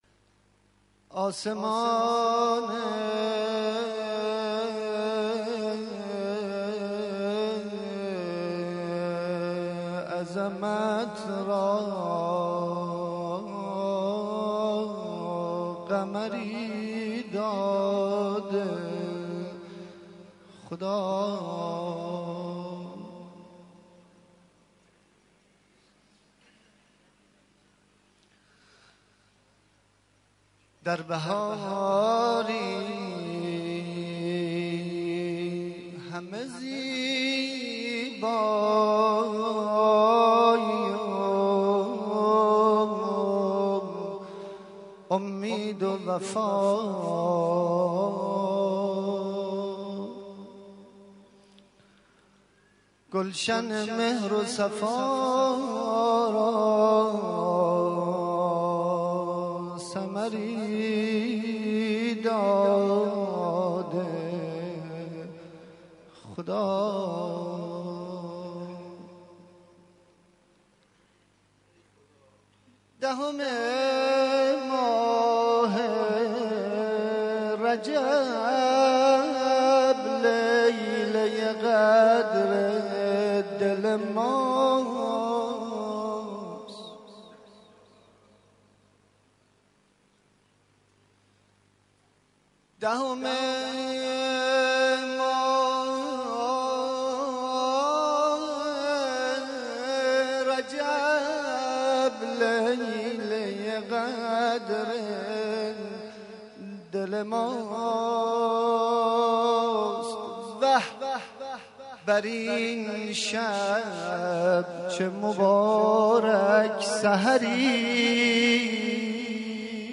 مدح مولودی